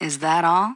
ZSS_voice_sample.oga.mp3